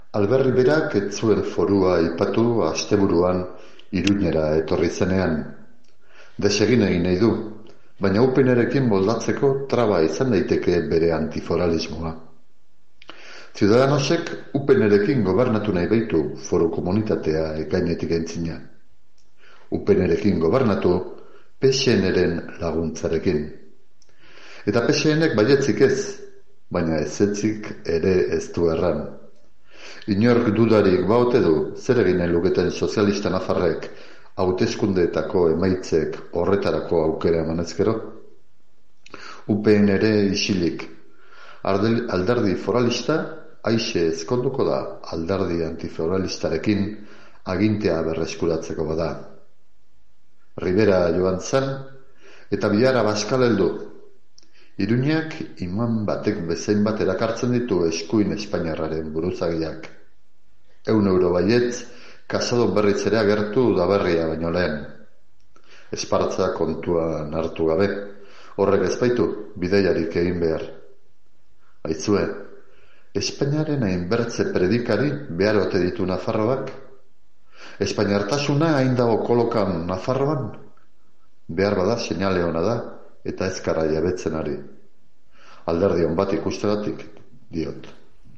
[Euskalerria Irratiko Metropoli Forala saioa, ‘Minutu bateko manifestua’, 2019ko otsailaren 6koa]